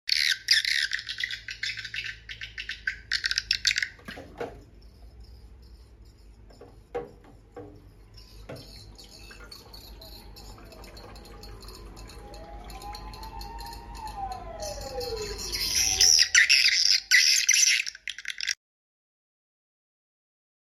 Audax Horn Tweeter AX 1R [For sound effects free download